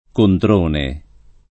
kontr1ne] o Controni [kontr1ni] top. (Tosc.)